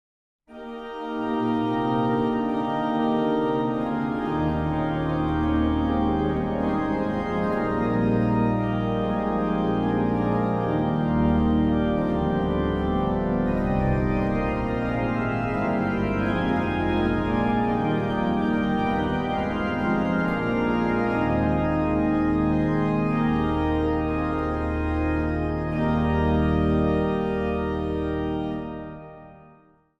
orgel.
Zang | Gemengd koor
Zang | Samenzang